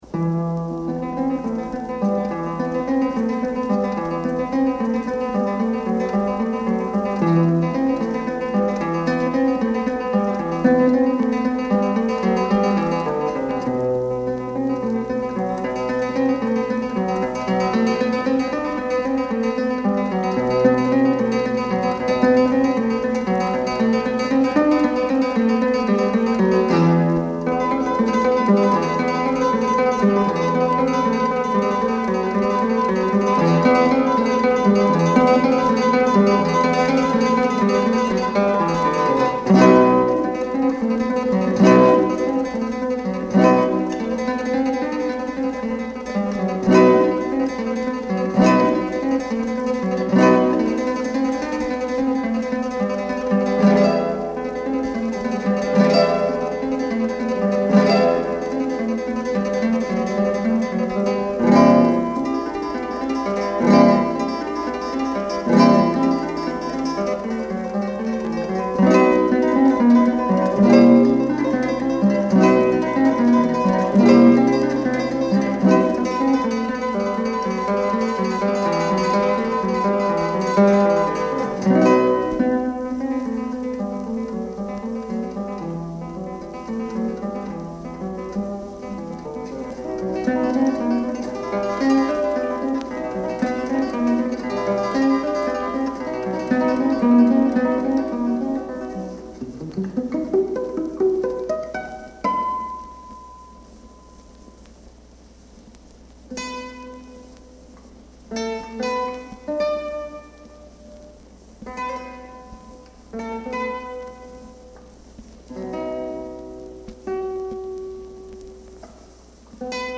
Genre: Classical Guitar